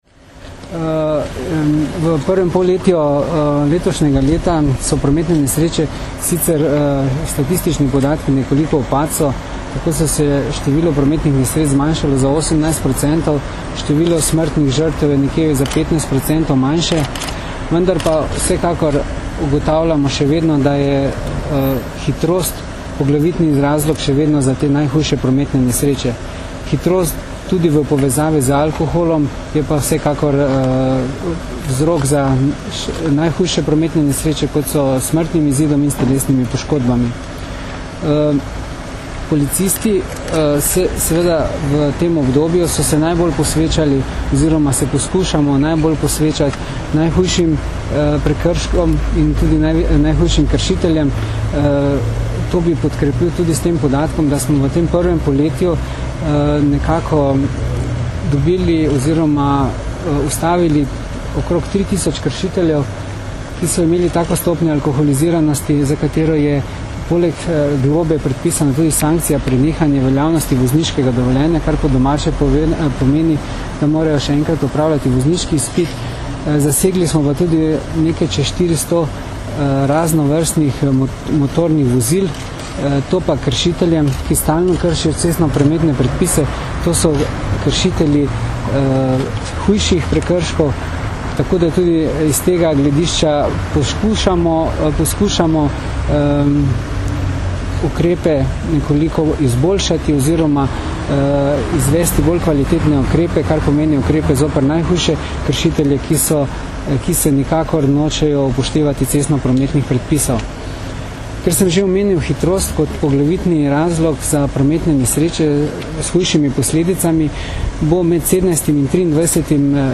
Zvočni posnetek izjave mag.